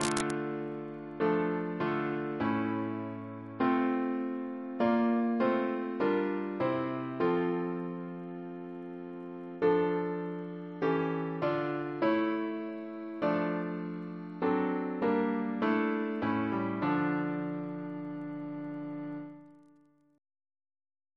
Double chant in D minor Composer: James Turle (1802-1882), Organist of Westminster Abbey Reference psalters: ACB: 110; PP/SNCB: 88; RSCM: 141